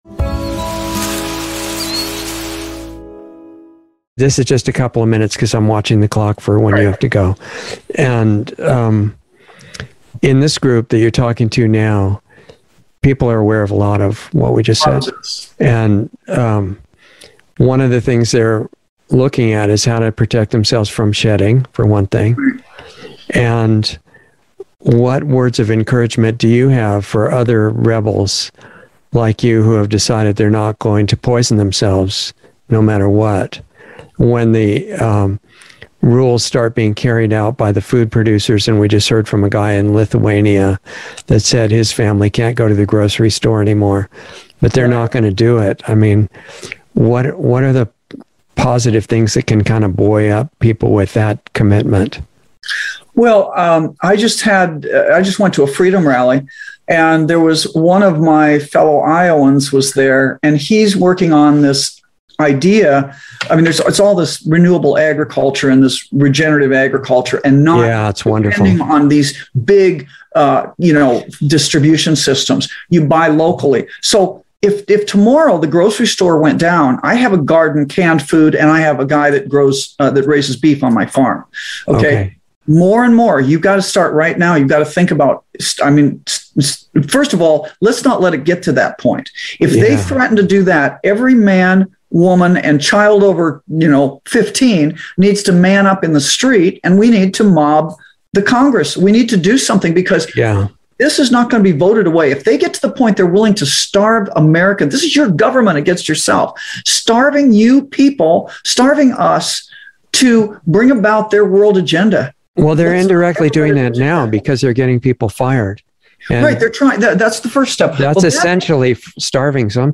Insider Interview 10/12/21 0:08:30 FHD Εγγραφείτε 19 lostartsradio 4 έτη 35 Προβολές donate Please login to donate MP3 Προσθήκη Want to watch this again later?